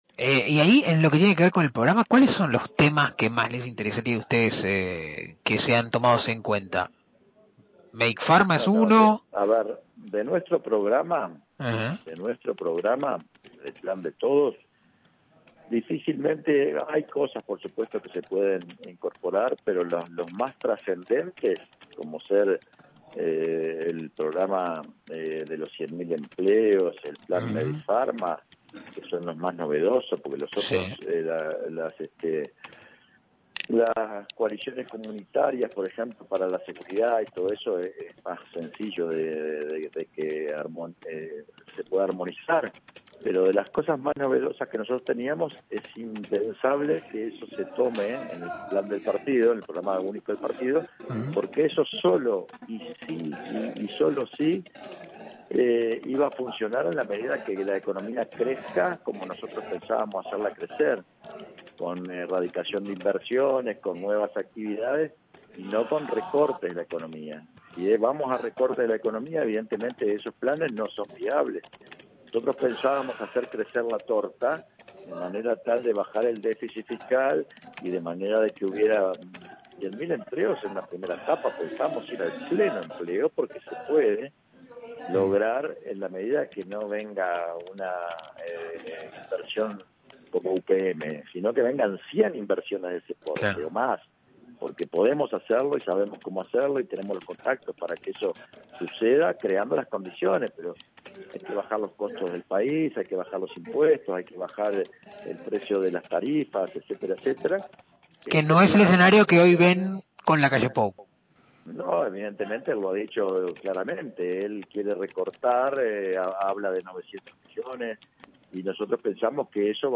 Acá, el audio completo del diálogo